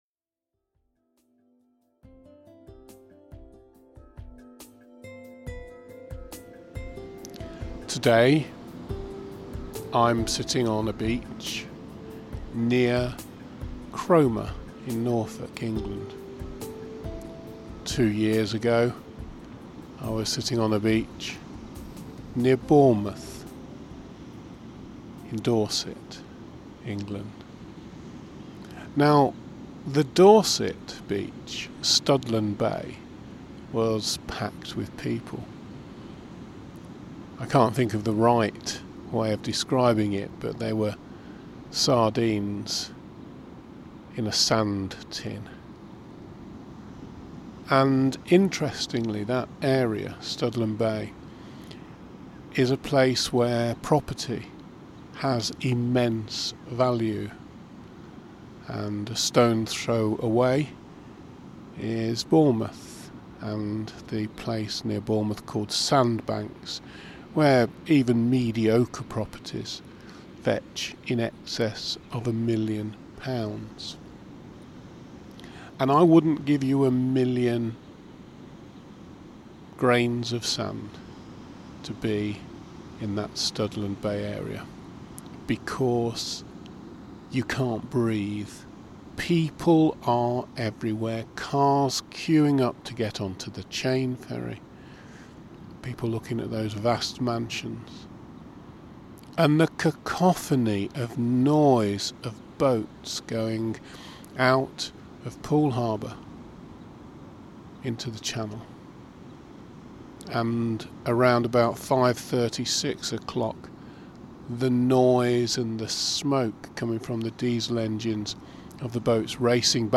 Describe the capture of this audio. My mind wanders: I make a recording it also free flows: the choice is to limit the editing: eight-minute-meditation-cromer-beach.mp3